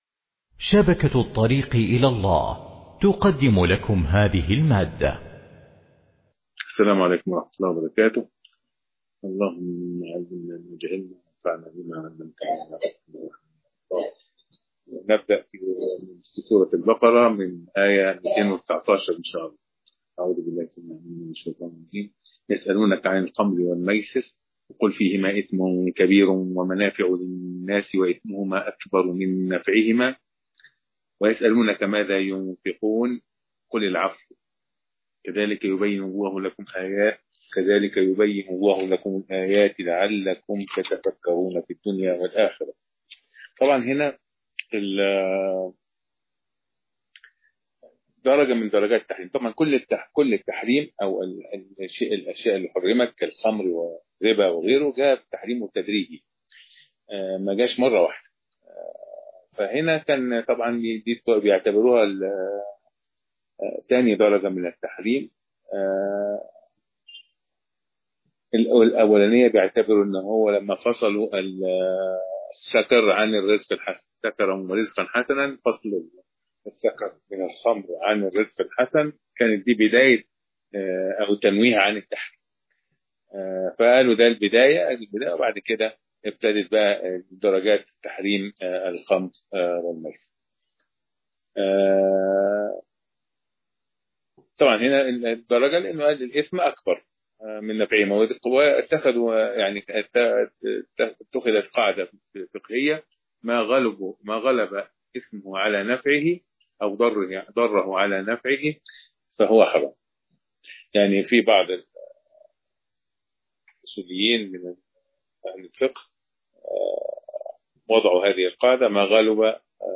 مقرأة